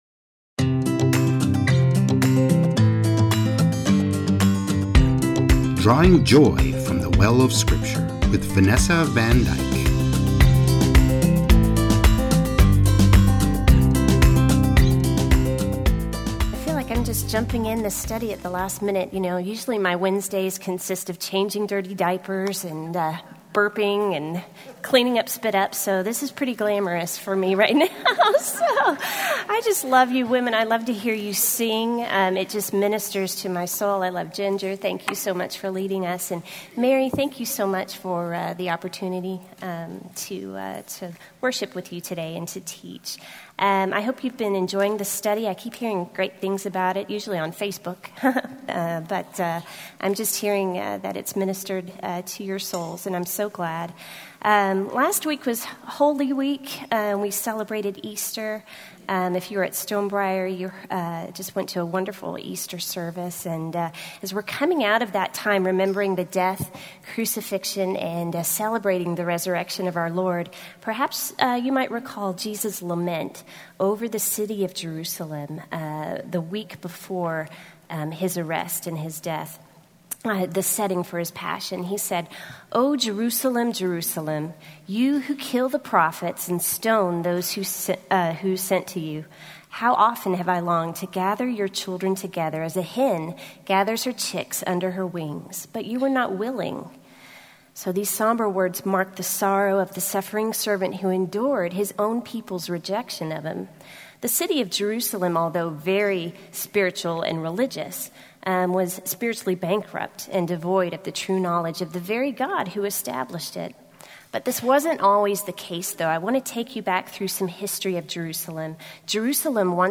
Rev-Lesson-10_mixdown.mp3